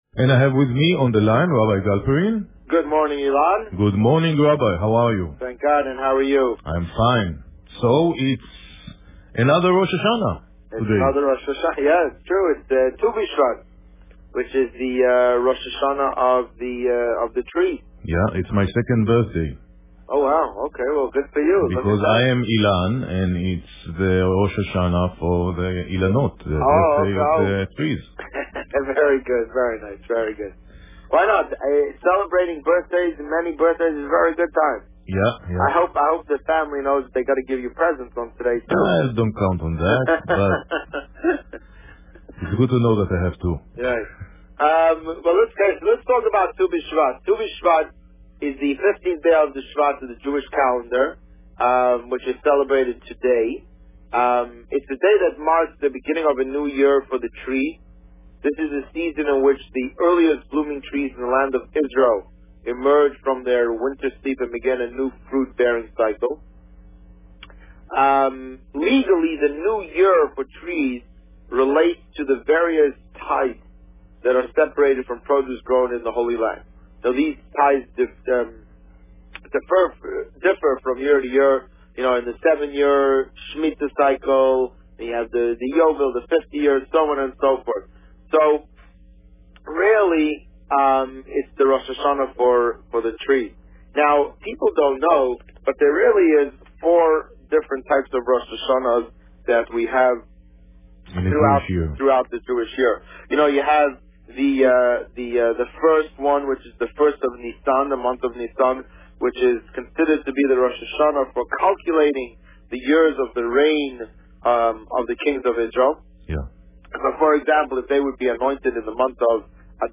You are here: Visitor Favourites The Rabbi on Radio The Rabbi on Radio Tu B'Shevat 2014 Published: 16 January 2014 | Written by Administrator This week, the Rabbi spoke about Tu B'Shevat. Listen to the interview here .